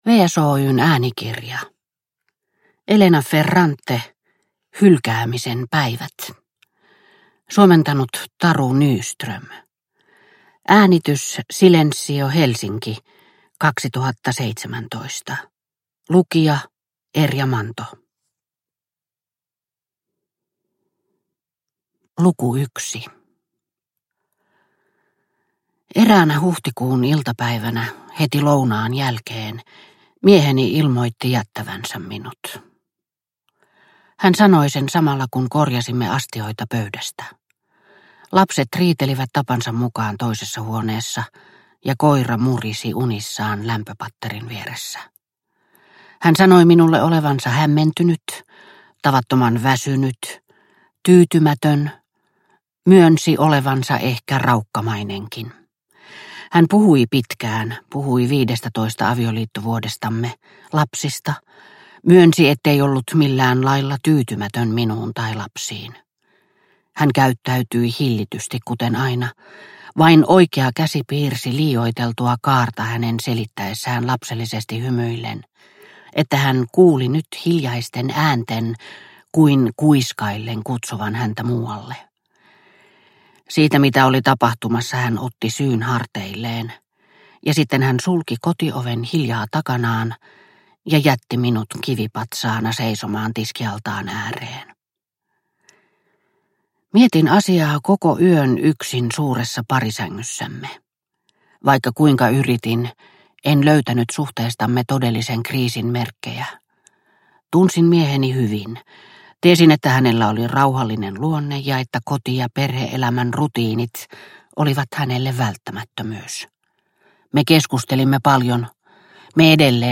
Hylkäämisen päivät – Ljudbok – Laddas ner